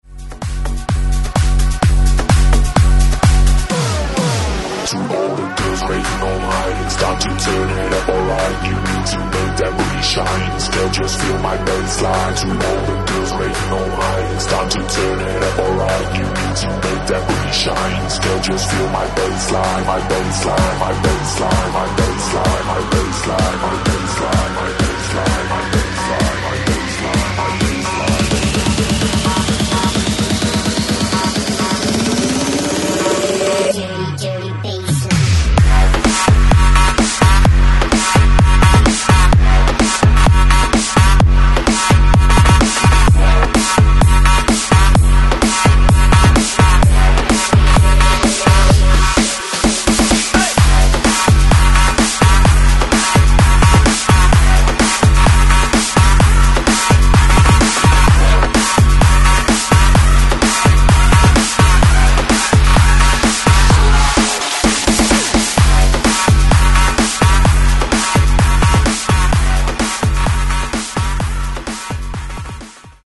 115 Genre: 80's Version: Clean BPM: 115 Time